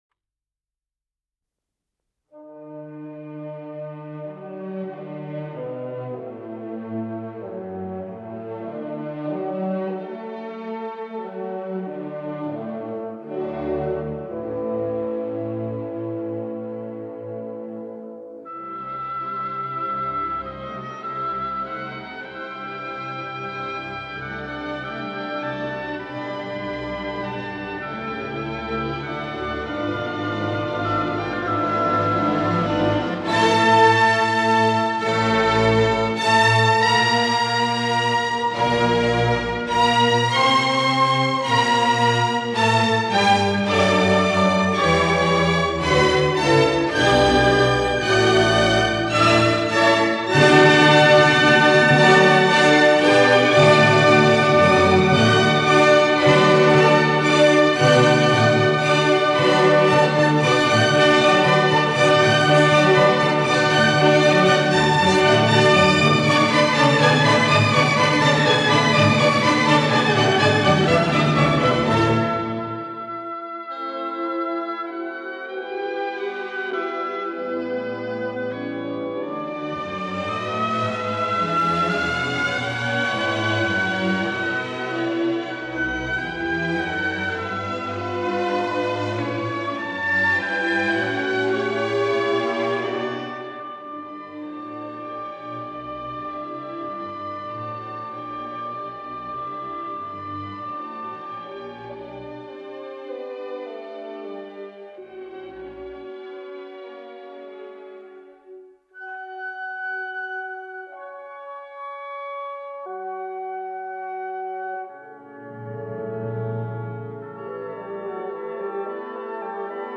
Исполнитель: И. Ойстрах - скрипка
Название передачи Скрипичный концерт, соч. 77 Подзаголовок Ре мажор Код ДКС-272 Фонд Без фонда (ГДРЗ) Редакция Не указана Общее звучание 00:38:45 Дата переписи 13.09.1962 Дата добавления 04.04.2022 Прослушать